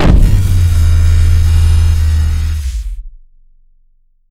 plasma-explosion-02.ogg